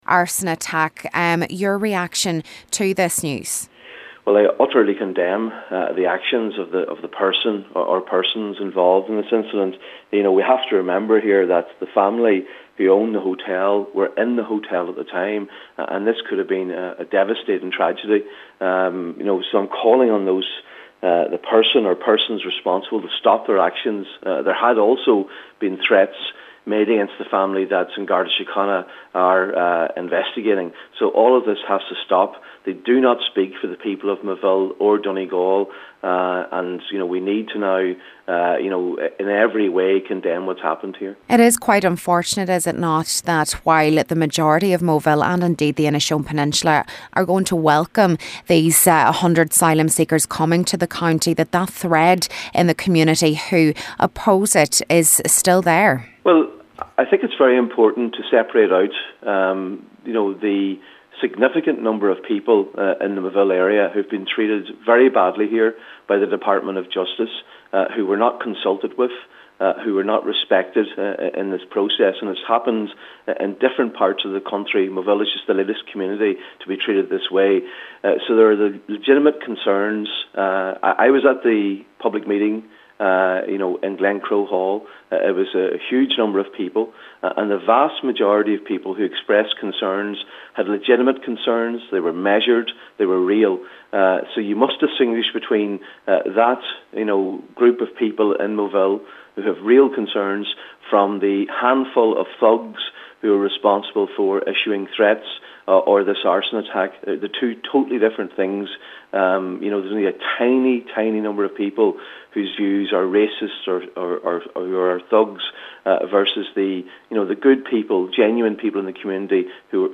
Donegal Senator Padraig Mac Lochlainn says the owners already received threats prior to the incident: